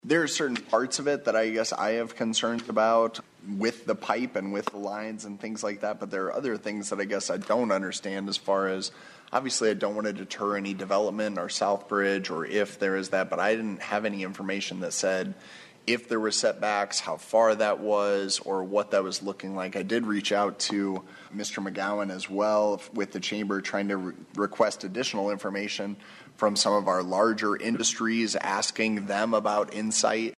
COUNCILMAN ALEX WATTERS ASKED FOR A DELAY TO OBTAIN MORE INFORMATION ON THE ISSUE: